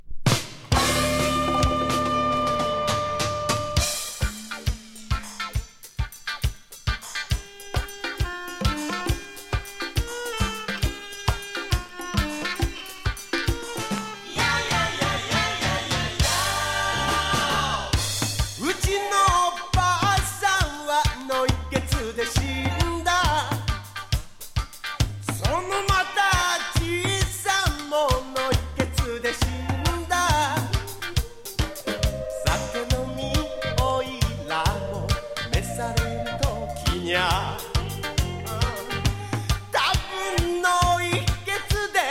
レゲエ歌謡